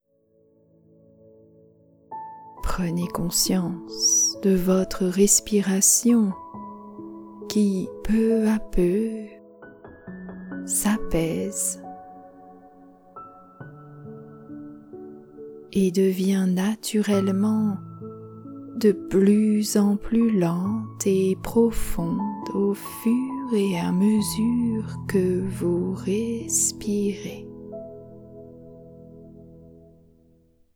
Cette longue relaxation guidée de 39 mn “Sommeil Régénérant” a été conçue pour favoriser un endormissement rapide et retrouver un sommeil paisible.